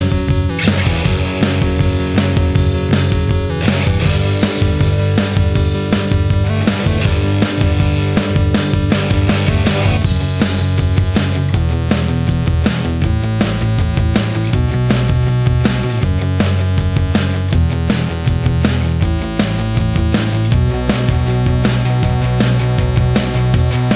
No Lead Guitar Pop (2000s) 3:35 Buy £1.50